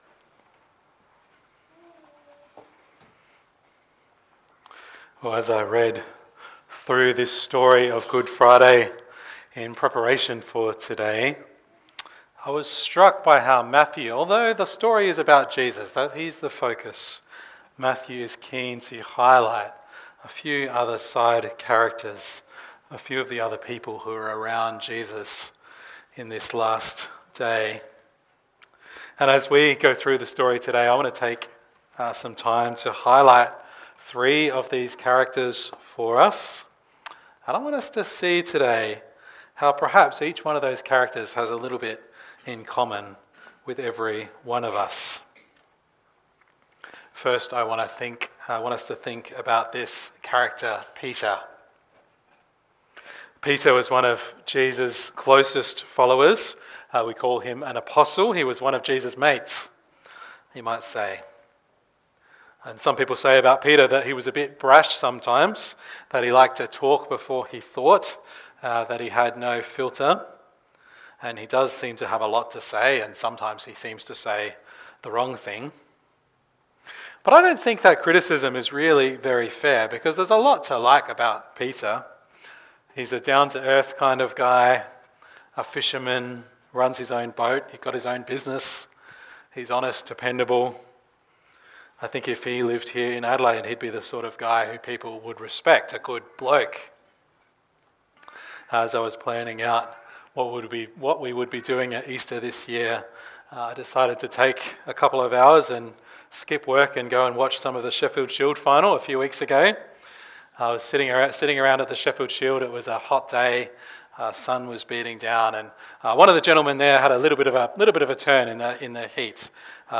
Three short talks from Matthew 26-27, as we reflected on the role of three characters in the story of Good Friday. These talks were originally interspersed with readings, prayer, reflection and song.